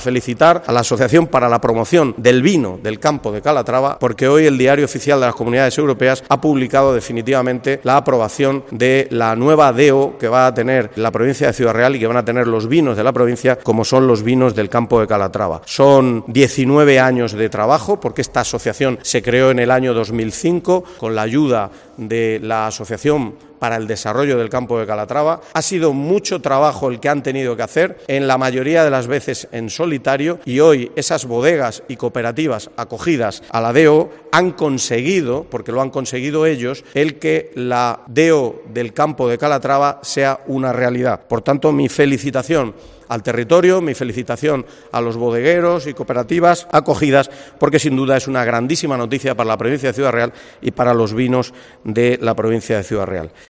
José Manuel Caballero, vicepresidente segundo Gobierno de Castilla-La Mancha